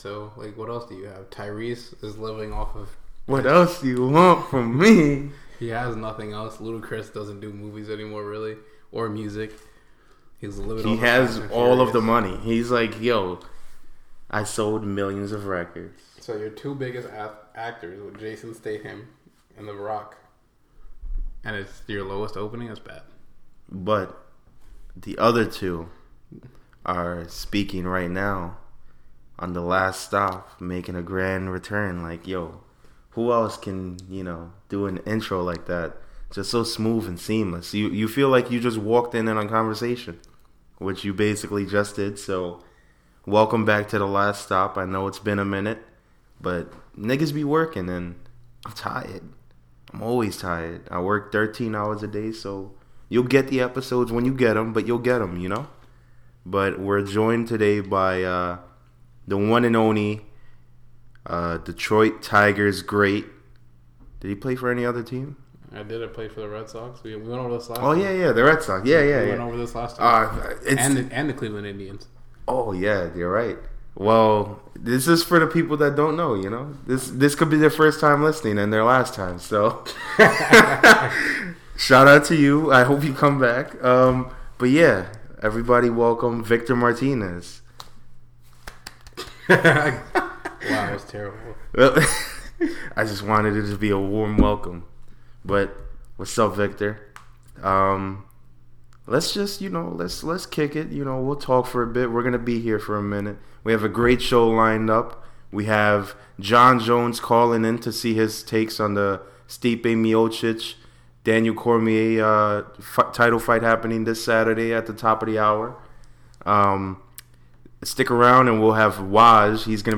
man just a couple guys talkin sports really. Listen in for some of our predictions for this weekends UFC card. Also we talk the NBA and Yankees.